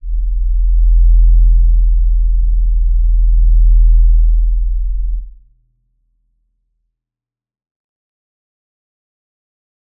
G_Crystal-E1-f.wav